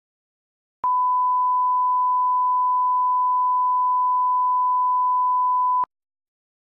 Random Beep Noise.....mp3